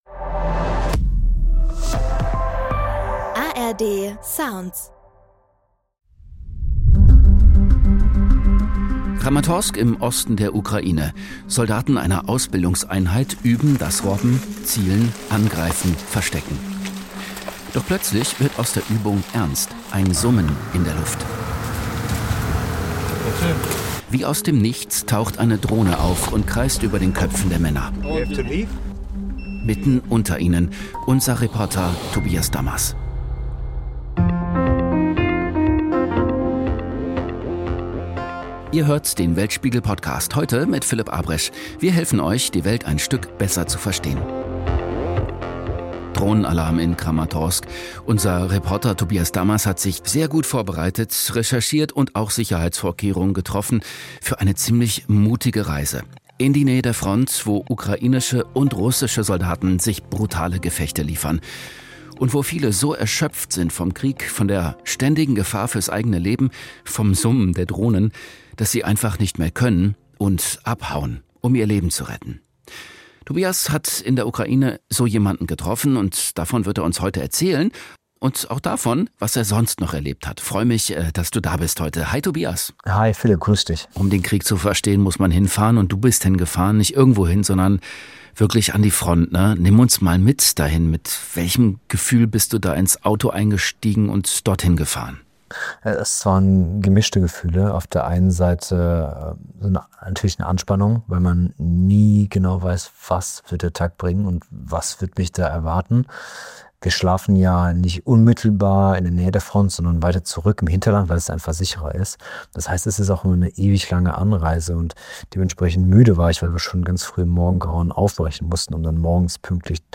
Kramatorsk im Osten der Ukraine: Eine Ausbildungseinheit übt – robben, zielen, angreifen, sich verstecken.
Ein Summen in der Luft, eine Drohne taucht auf und kreist über den Köpfen der Soldaten.